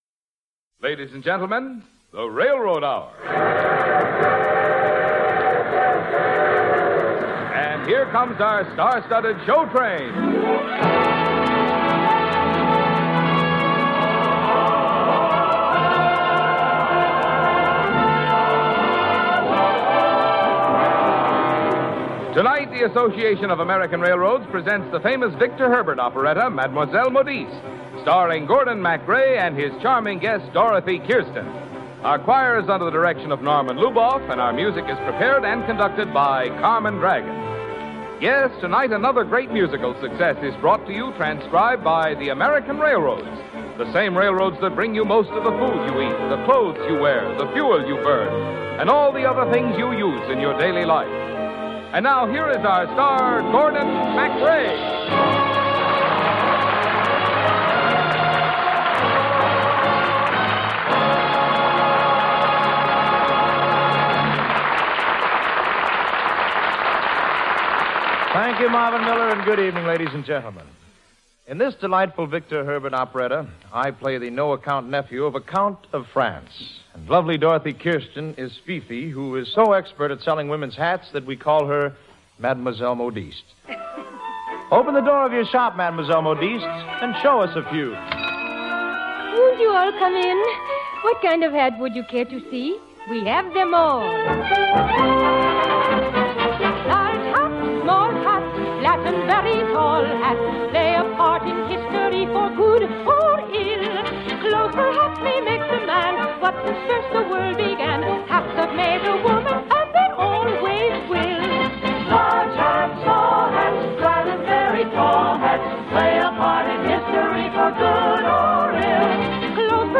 radio series that aired musical dramas and comedies
hosted each episode and played the leading male roles